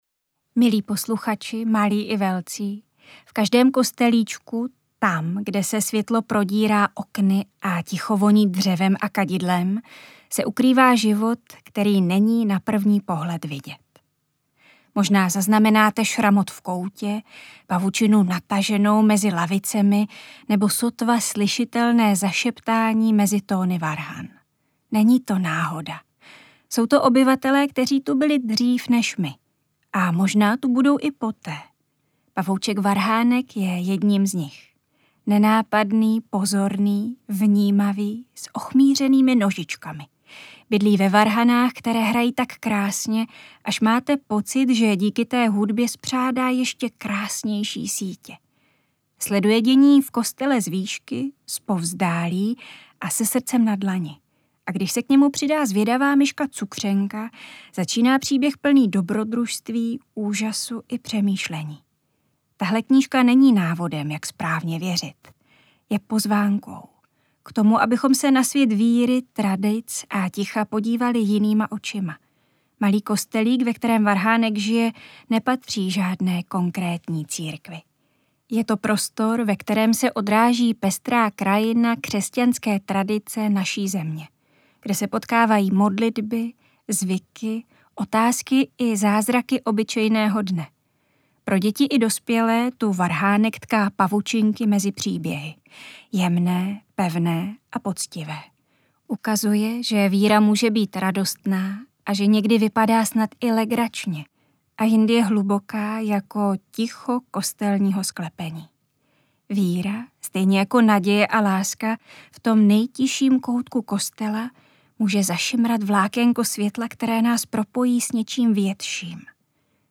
Interpret:  Petr Čtvrtníček